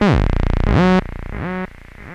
Loudest frequency 497 Hz Recorded with monotron delay and monotron - analogue ribbon synthesizer